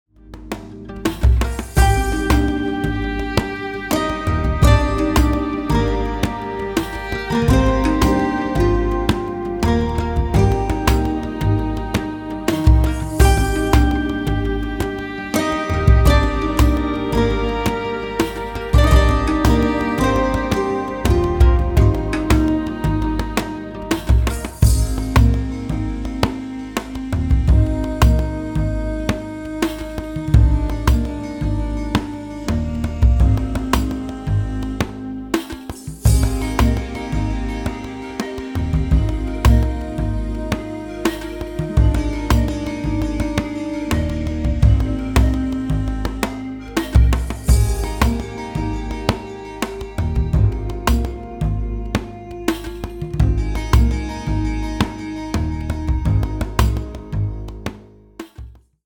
Ethnic Ambience